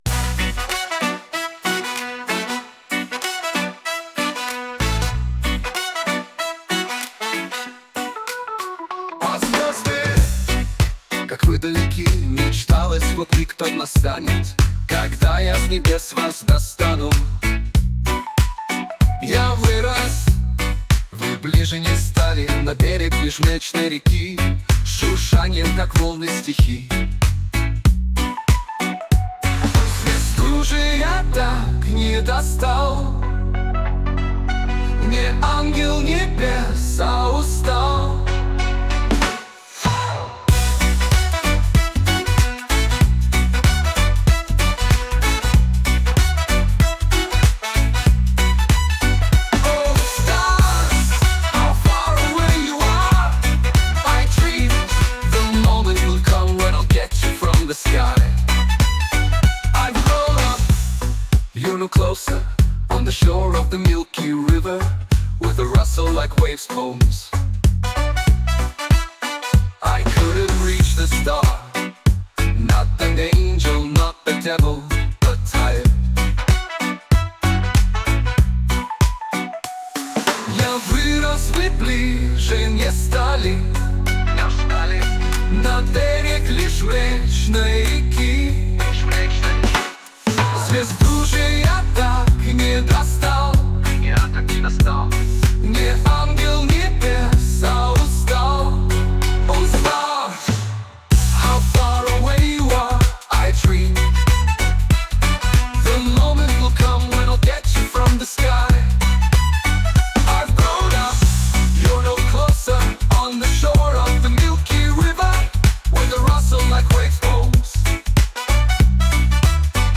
Песня на трех языках, была написана лет 35 назад.